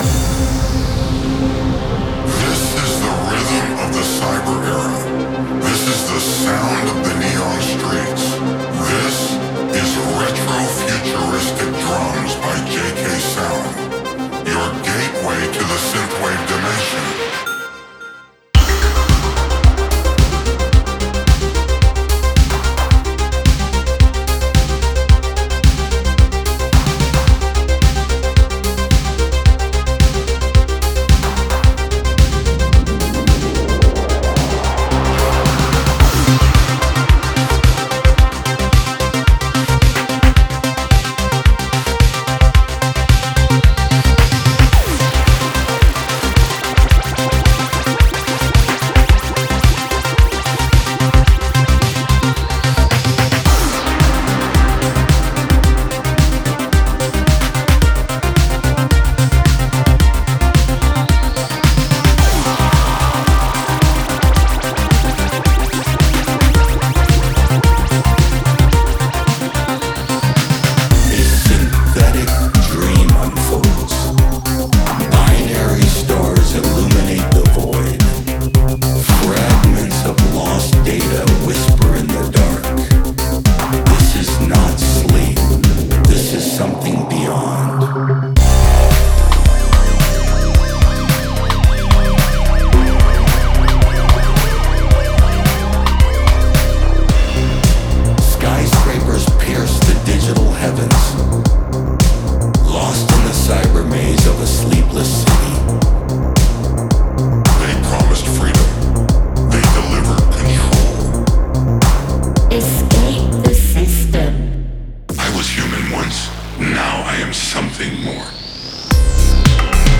Multi-genre Synthwave / Retrowave
A classic sci-fi sound , reimagined.
Experience the power of vintage drum machines with a futuristic twist!
This sample pack blends drone textures with deep,
Ready for production – perfect synthwave, electro, cyberpunk,retrowave and more.